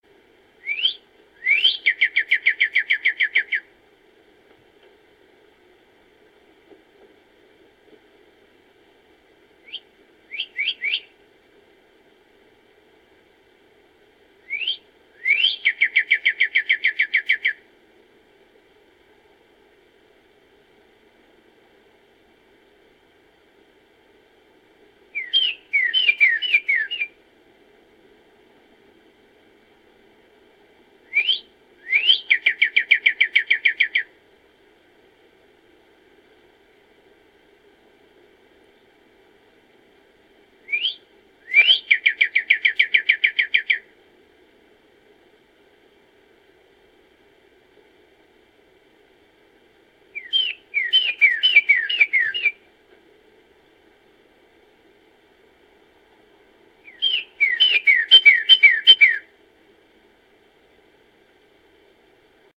Bird_singing.ogg